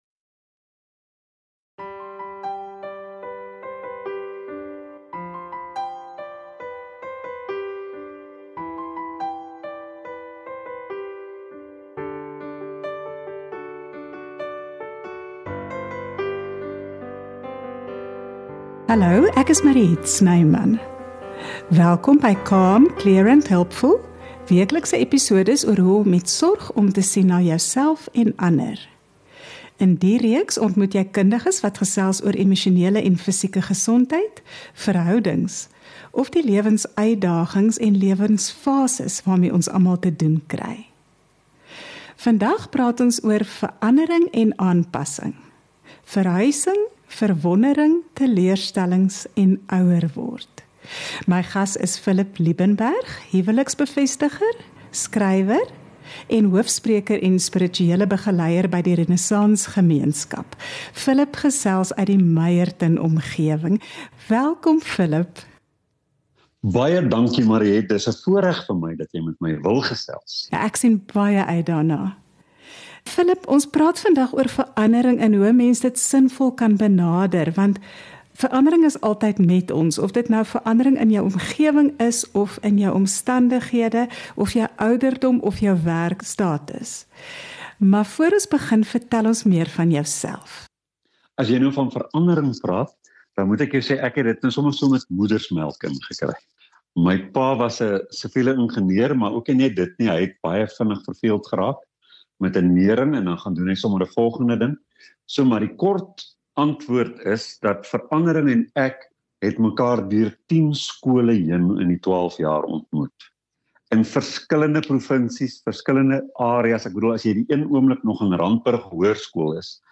interviews a range of experts on holistic health, love relationships, parenting, and life's phases and challenges.